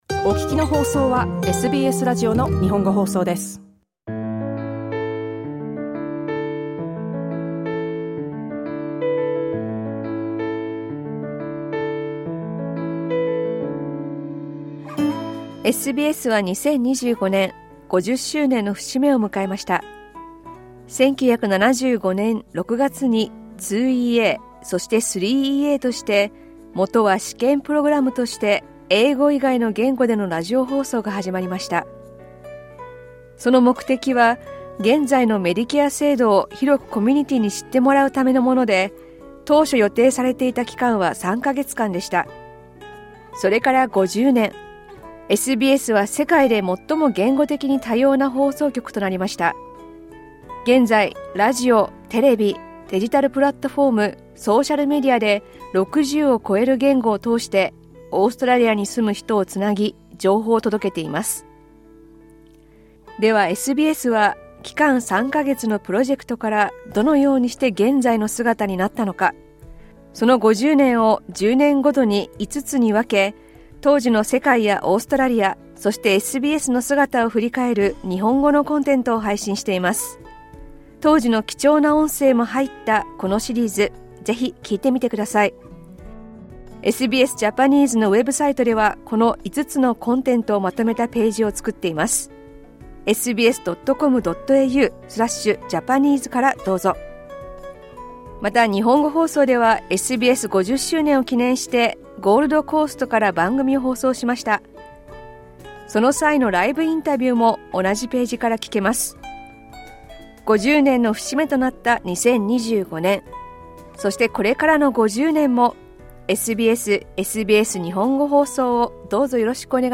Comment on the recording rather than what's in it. As SBS turns 50 in 2025, join us in reflecting on its history through a Japanese podcast, tracing its journey alongside changes in Australia and the world. The podcast features historically significant audio recordings.